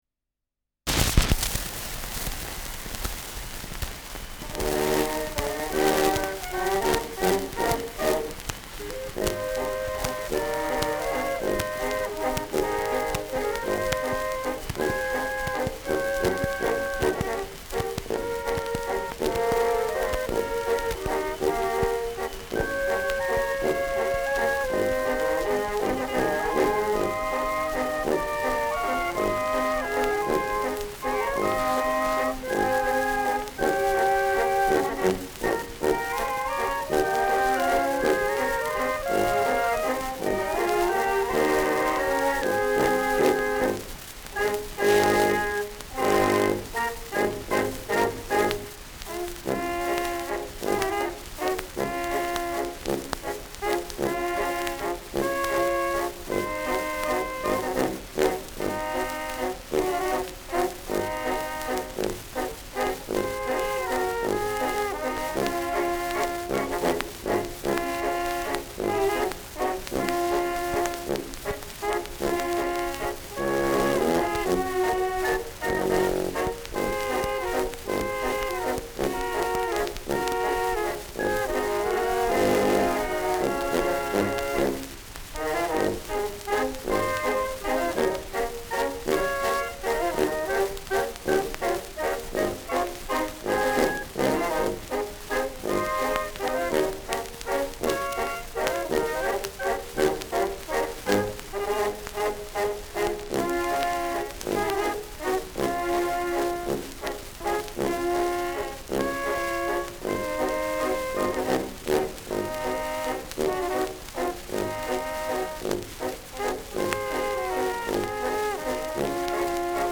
Schellackplatte
Abgespielt : Starkes Grundrauschen : Nadelgeräusch : Gelegentlich leichtes Knacken : Leichtes Leiern
Alpenländer Bauernkapelle (Interpretation)